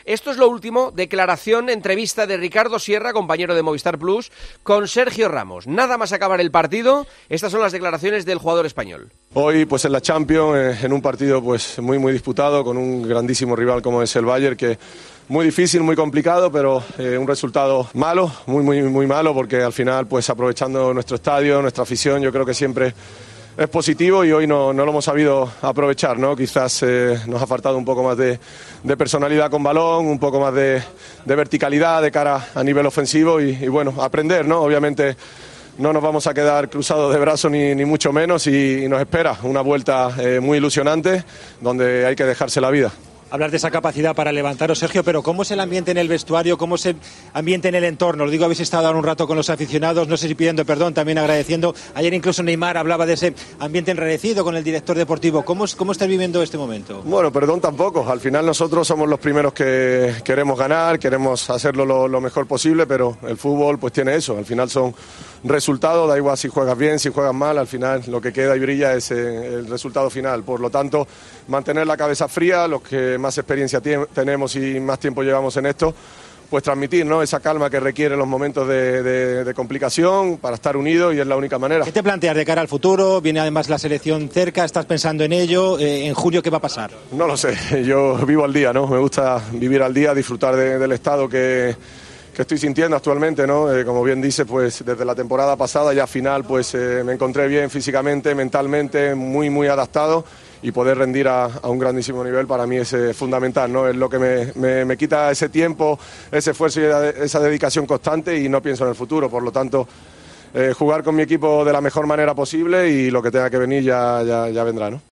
El central español analizó en Movistar la derrota contra el Bayern.